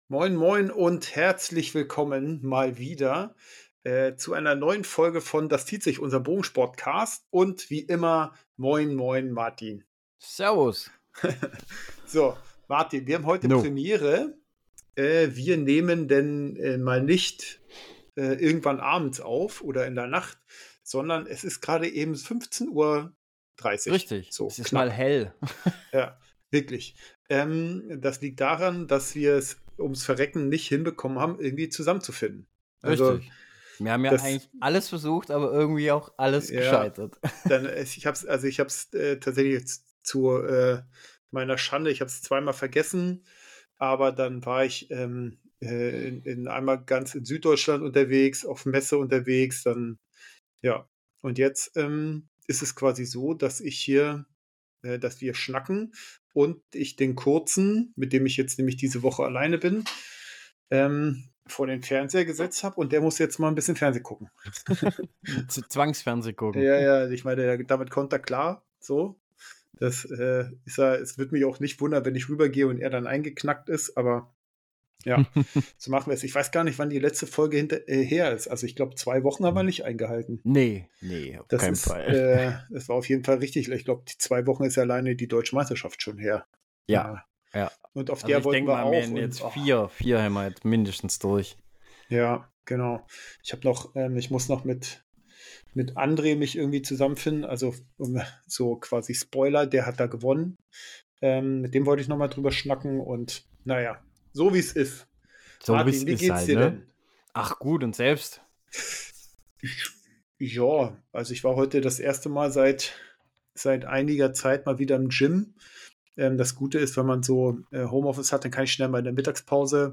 Zwei Bogenschützen reden sich um Kopf und Kragen … Themen: Bogensport, Equipment,3D, Feldbogensport, Turniervorbereitung, Training, Tipps und Tricks Bei dieser Podcast-Serie handelt es sich um einen externen Inhalt.